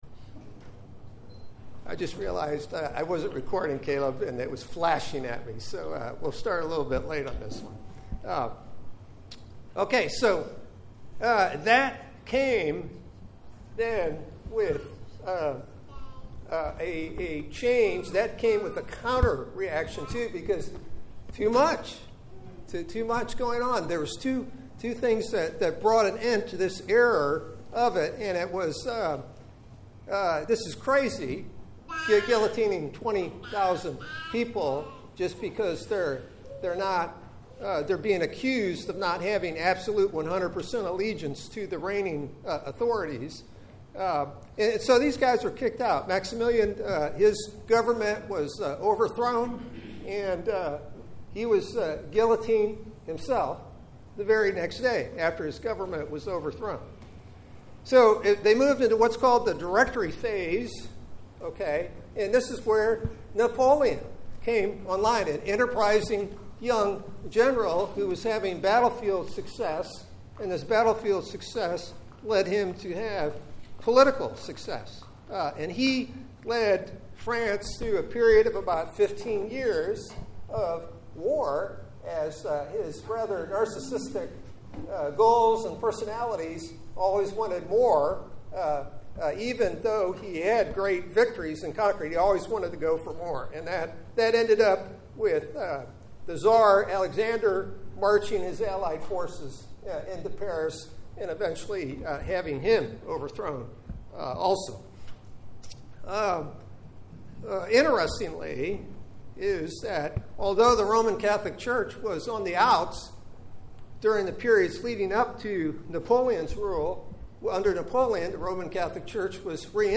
Sunday School | Trinity Presbyterian Church